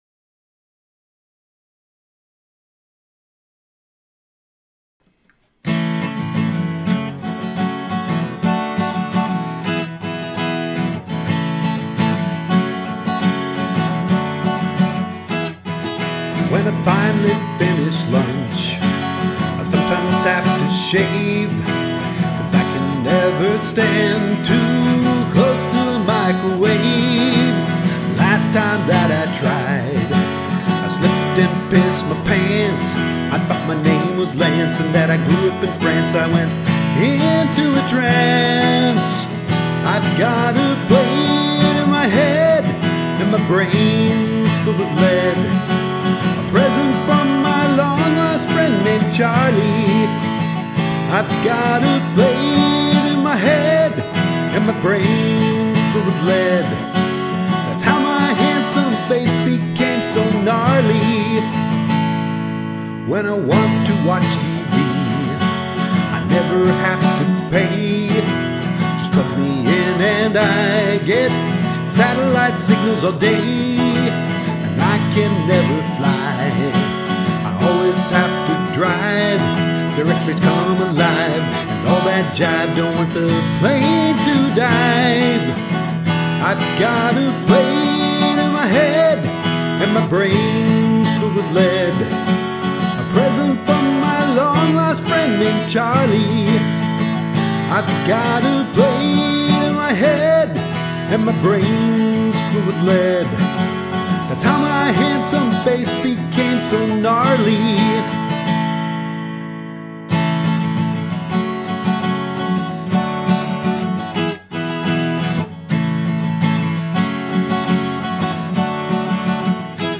Genre: acoustic rock, singer/songwriter.